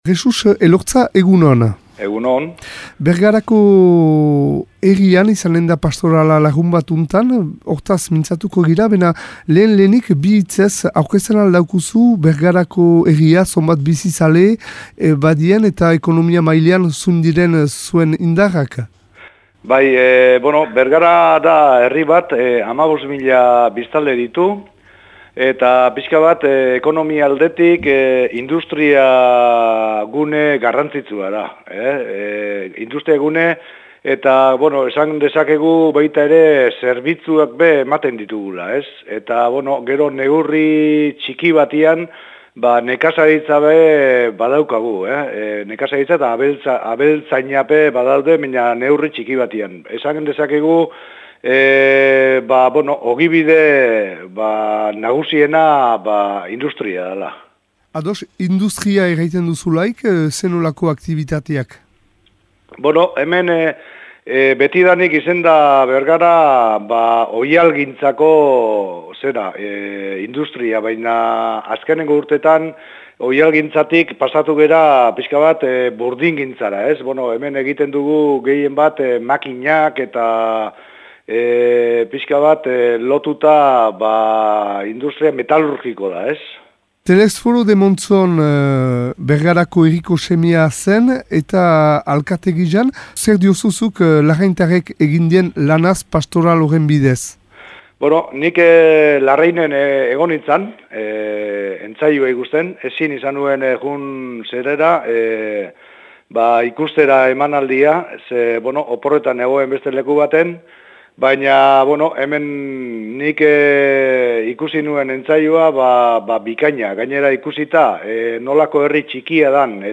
Entzün Jesus Elorza Bergarako aüzapeza :